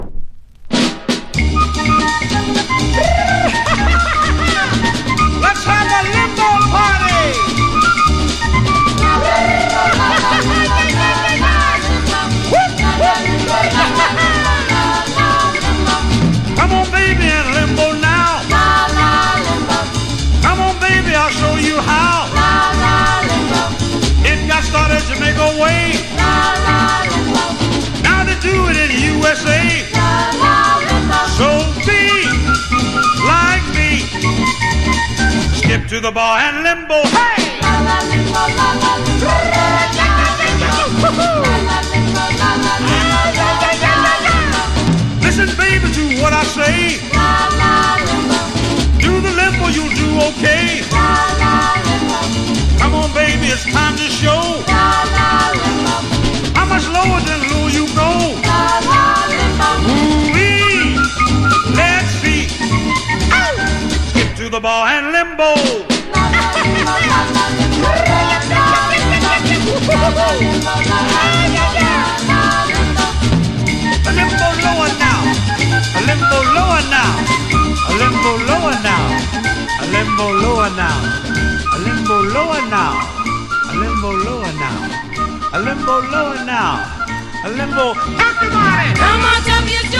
# LATIN
• 盤面 : VG (スレ・キズ多い) | 薄スレ・キズ等が多く、ノイズあり。